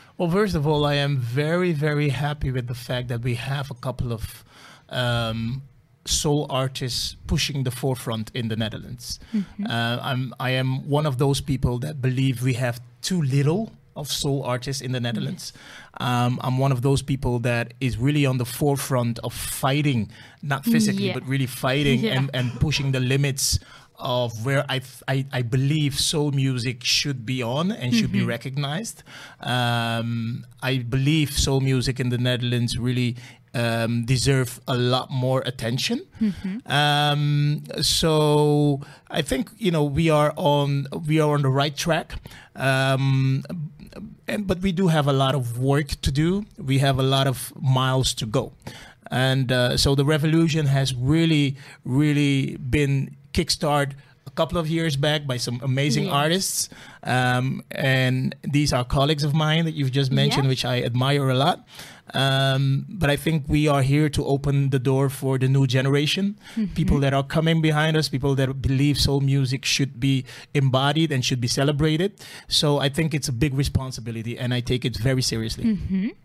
Però abans que sonin les primeres notes del seu nou treball, ‘Soul Revolution’, hem tingut l’honor que visiti l’estudi de Ràdio Capital.